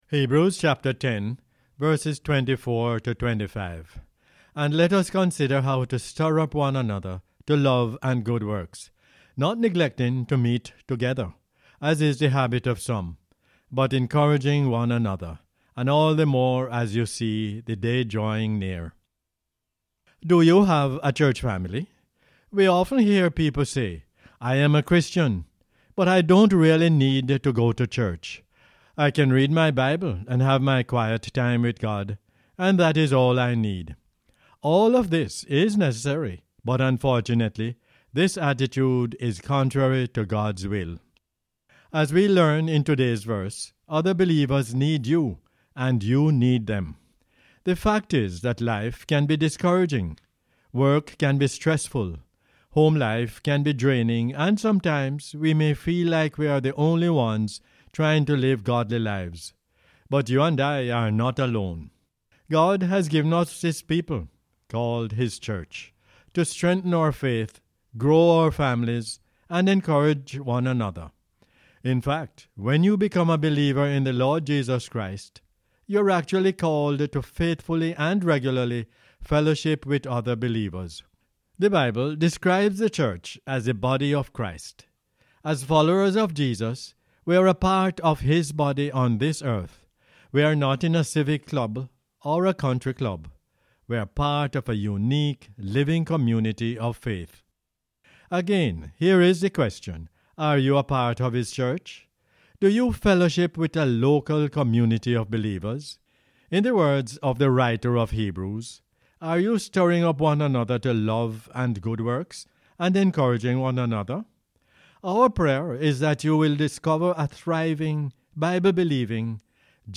Hebrews 10:24-25 is the "Word For Jamaica" as aired on the radio on 16 September 2022.